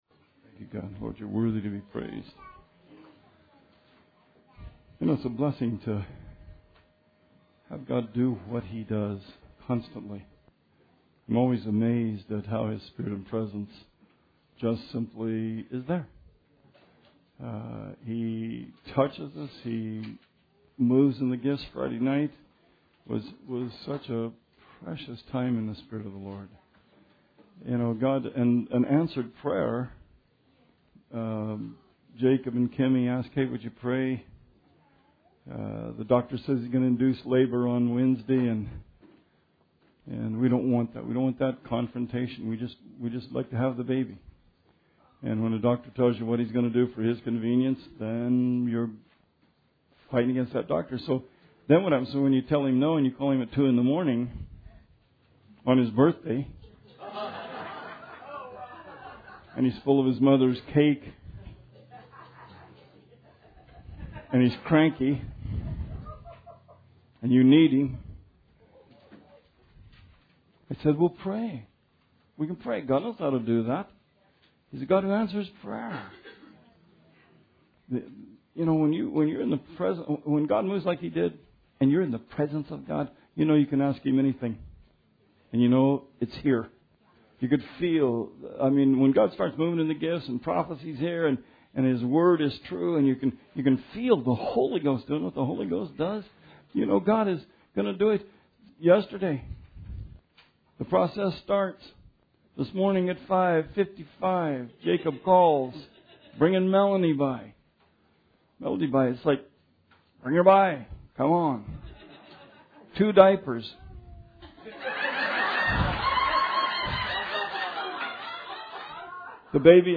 Sermon 6/12/16